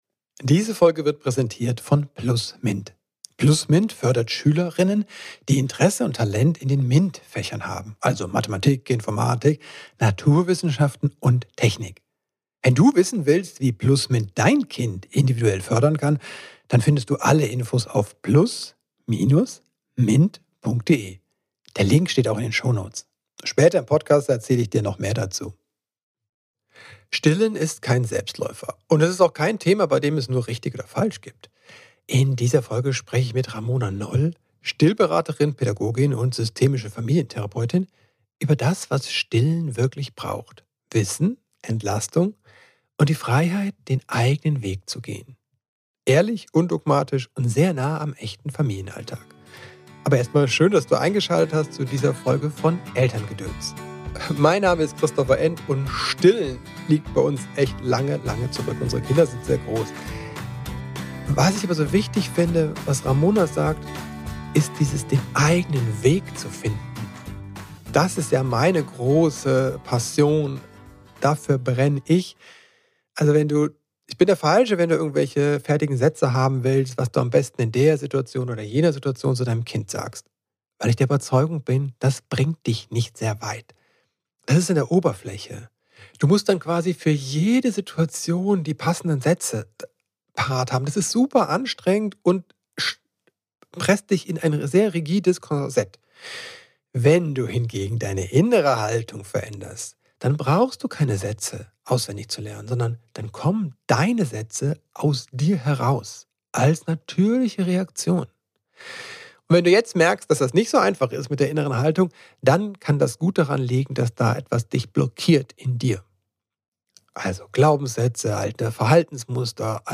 Diese angeleitete Visualisierungsübung hilft dir das alte Jahr zu verabschieden, Kraft zu tanken aus deinen Erfahrungen der letzten 12 Monate und dich auf das neue Jahr vorzubereiten.